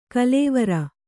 ♪ kalēvara